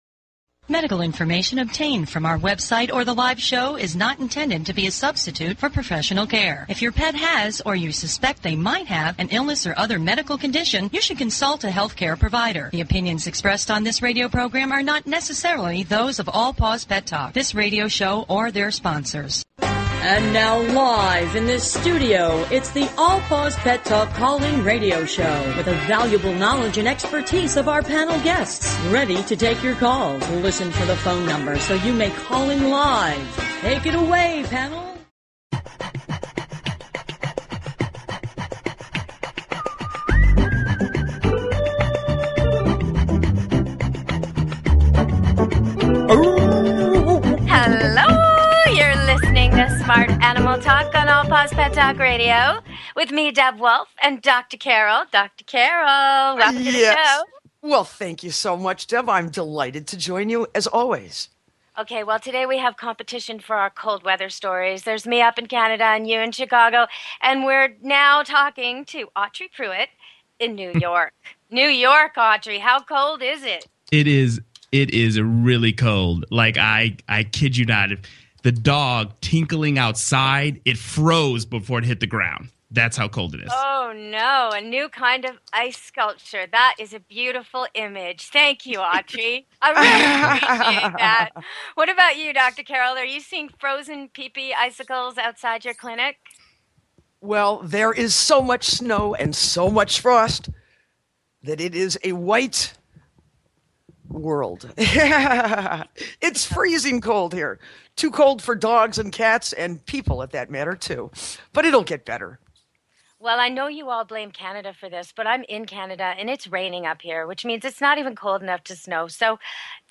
Talk Show Episode, Audio Podcast, All_Paws_Pet_Talk and Courtesy of BBS Radio on , show guests , about , categorized as
All Paws Pet Talk is directed to the millions of owners who are devoted to their pets and animals. Our hosts are animal industry professionals covering various specialty topics and giving free pet behavior and medical advice. We give listeners the opportunity to speak with animal experts one on one.